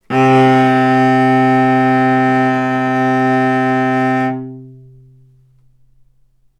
healing-soundscapes/Sound Banks/HSS_OP_Pack/Strings/cello/ord/vc-C3-ff.AIF at 61d9fc336c23f962a4879a825ef13e8dd23a4d25
vc-C3-ff.AIF